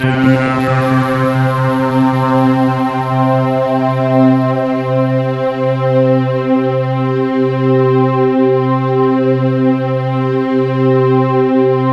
Index of /90_sSampleCDs/USB Soundscan vol.13 - Ethereal Atmosphere [AKAI] 1CD/Partition C/06-POLYSYNTH